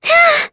Lavril-Vox_Attack4.wav